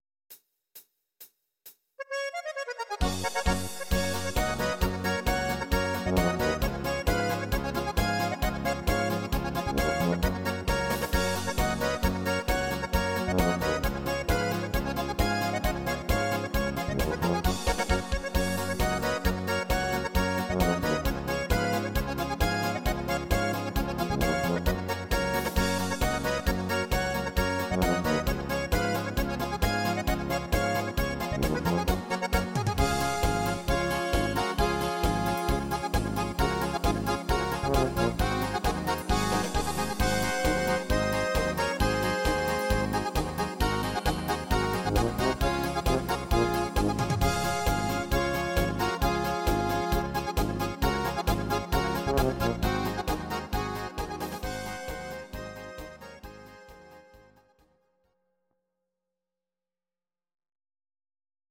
Audio Recordings based on Midi-files
Instrumental, Traditional/Folk, Volkst�mlich